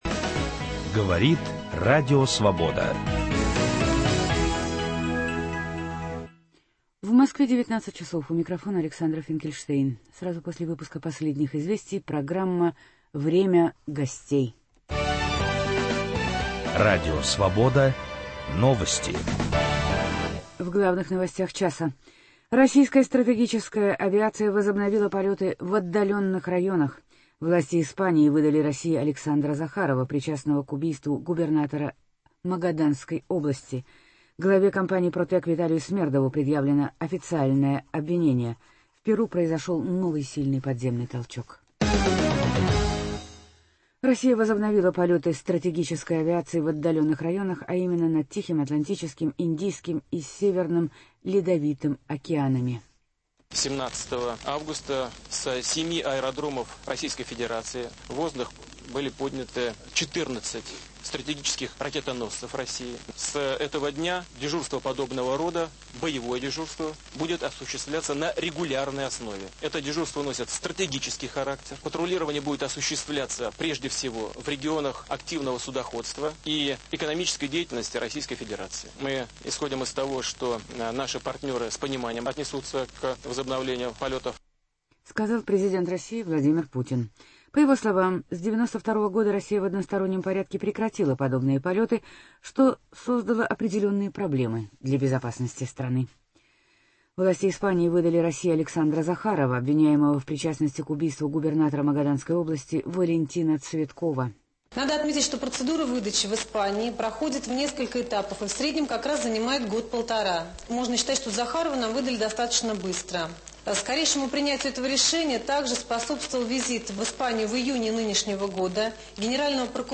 Гость - Борис Немцов, бывший вице-премьер, бывший лидер СПС, член федерального политсовета, автор книги «Исповедь бунтаря».